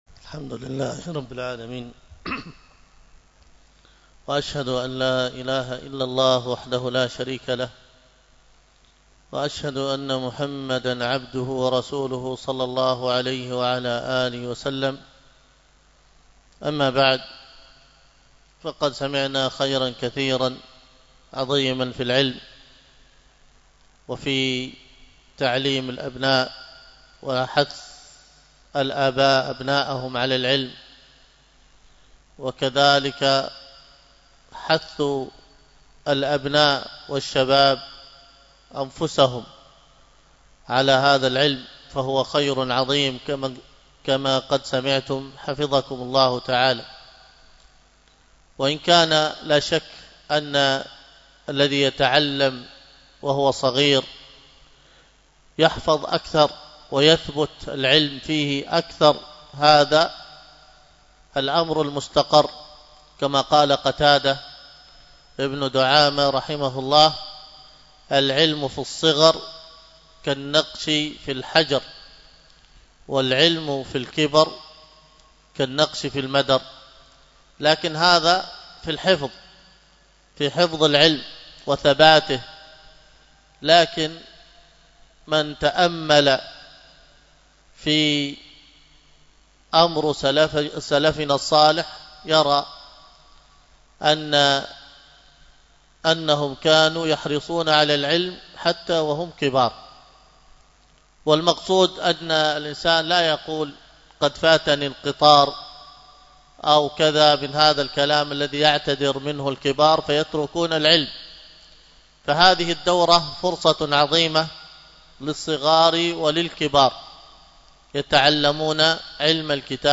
المحاضرة بعنوان أحكام رمضانية، والتي كانت ضمن دورة بين يدي رمضان بمسجد التقوى بدار الحديث بالشحر 25 شعبان 1446هـ الموافق 24 فبراير 2025م